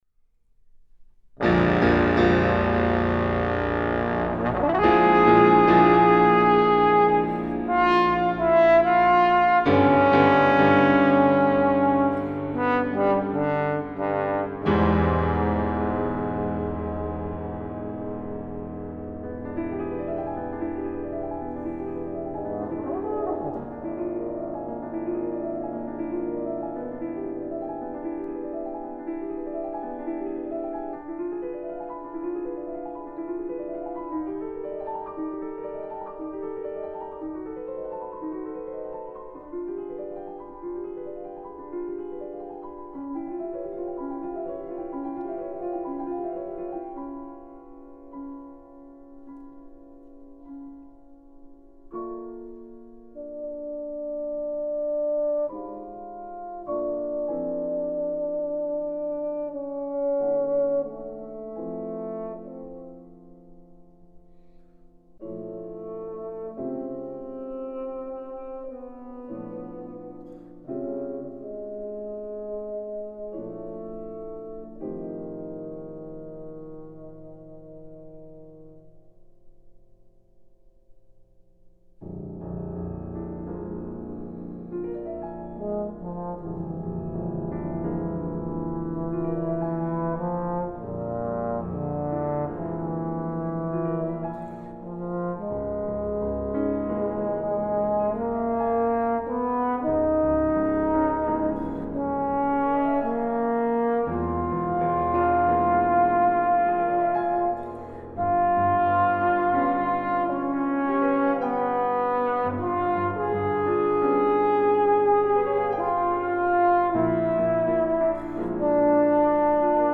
Piano
Tenor trombone